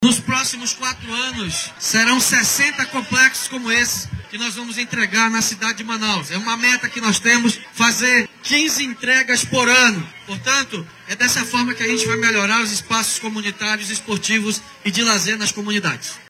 SONORA-1-PREFEITO-DAVI-ALMEIDA.mp3